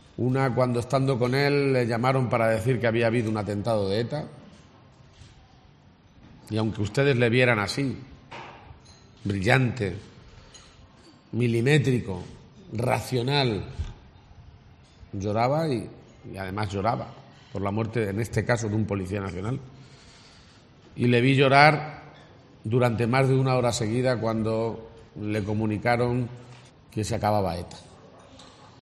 "Se nos acaba de marchar", ha anunciado García-Page en mitad de una comida en San Clemente (Cuenca), donde ha lamentado el fallecimiento del dirigente socialista y ha destacado que "España no deja de ser más grande" pero sí "por dejar de tener a alguien que ha hecho mucho por ella".